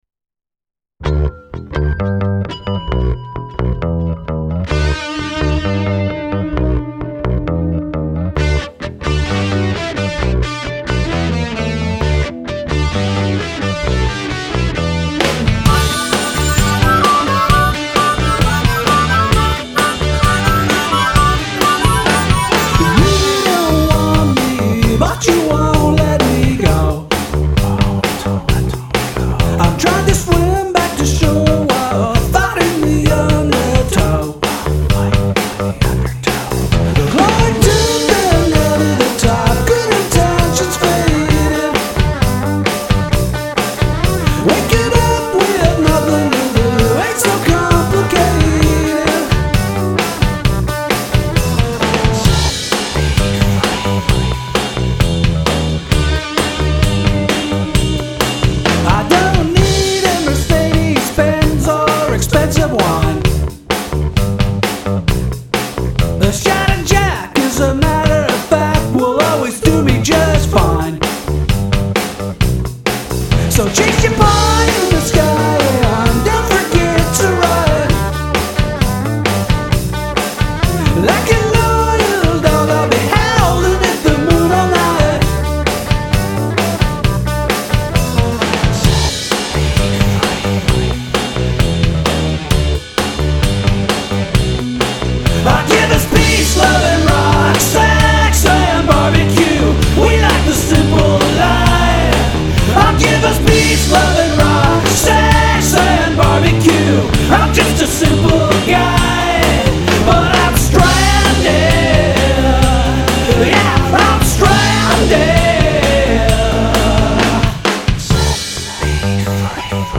Song must include whispering